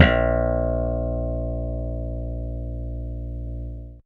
55v-bse08-a#2.aif